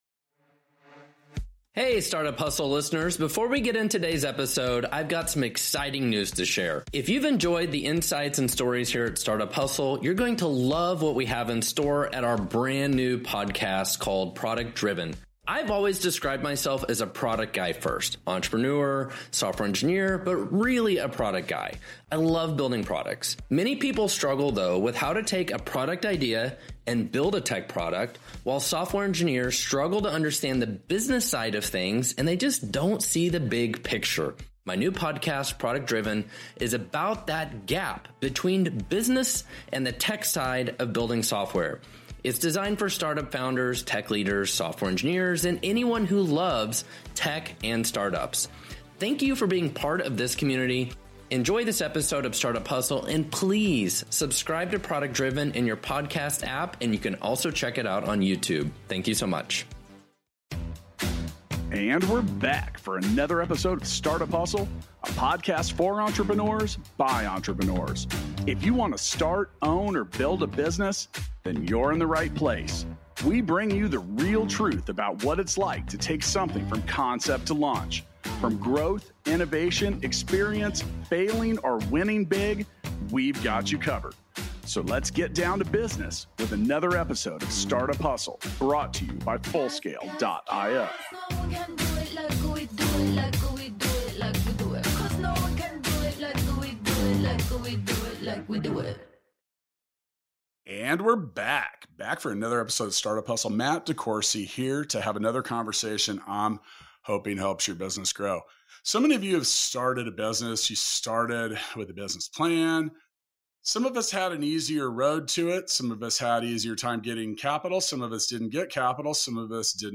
Enjoy this exciting conversation about America's new business plan. Learn about your options in finding capital for your business, even when you are part of a minority.